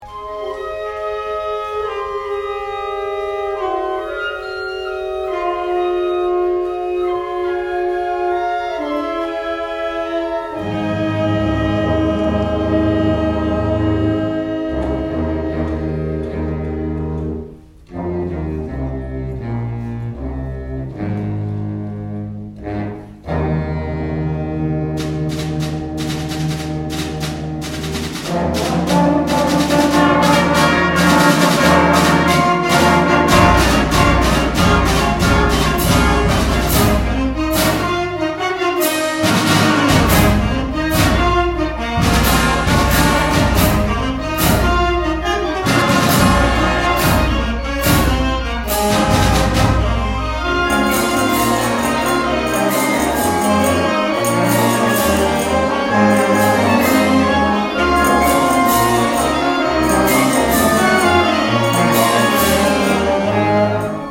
第１４回南那須地区音楽祭にブラスバンド部が出場しました。
迫力ある演奏をお聴きください。